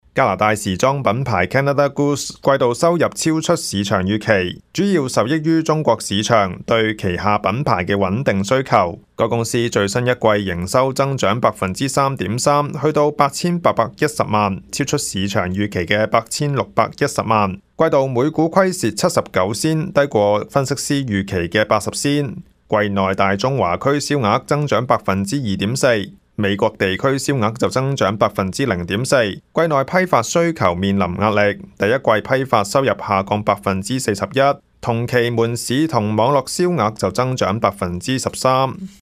news_clip_19998.mp3